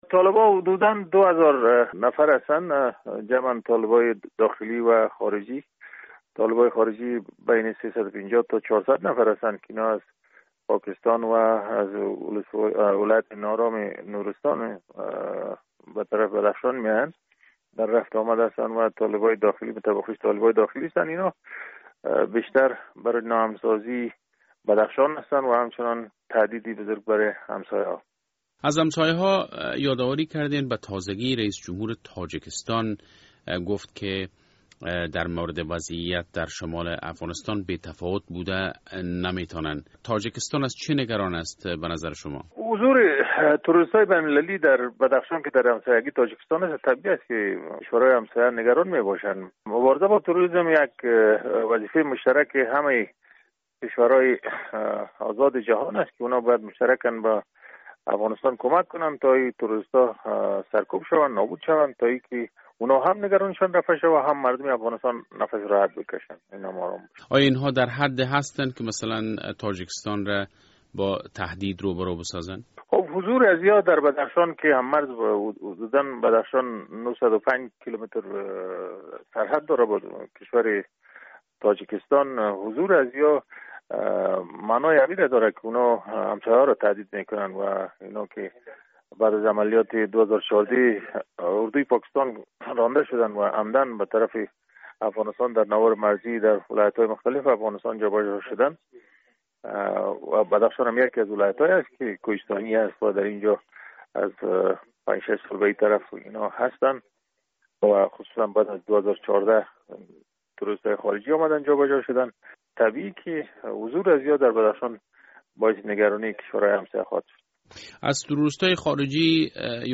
او دیشب در صحبت تیلیفونی با رادیو آزادی بیشتر گفت:
فیصل بیگزاد والی بدخشان